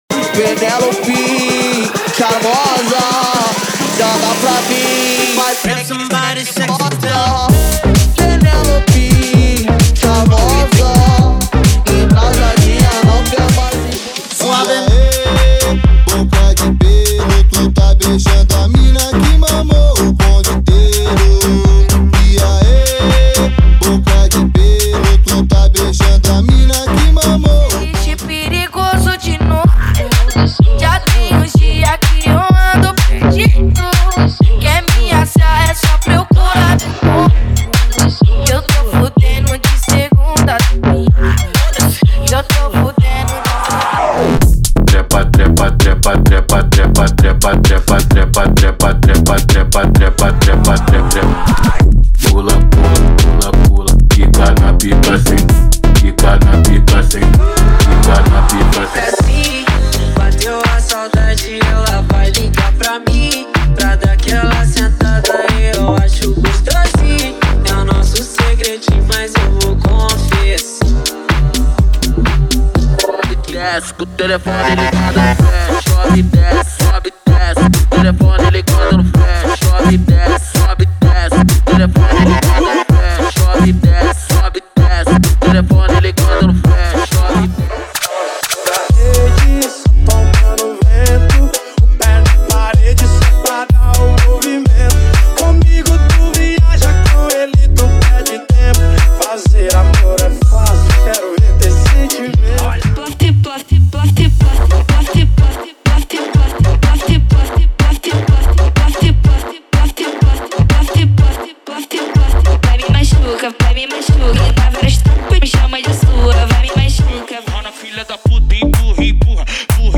MEGA FUNK = 50 Músicas
Sem Vinhetas
Em Alta Qualidade